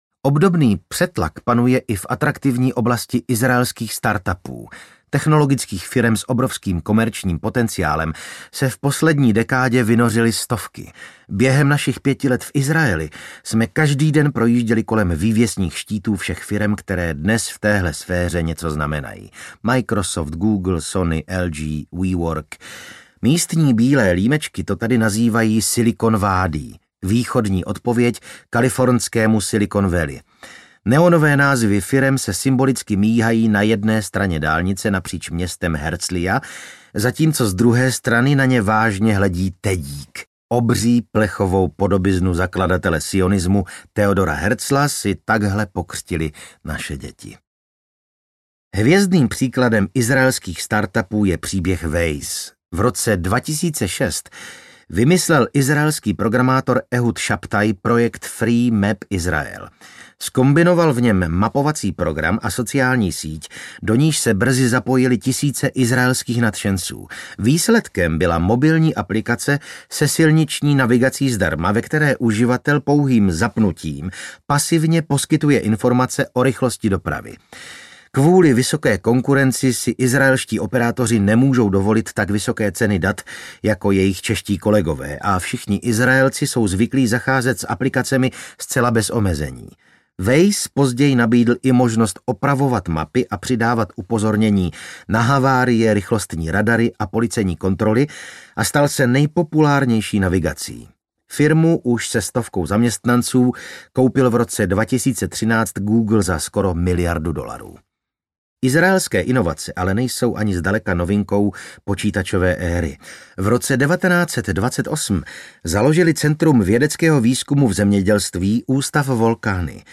Z Izrastiny s láskou audiokniha
Ukázka z knihy
• InterpretVasil Fridrich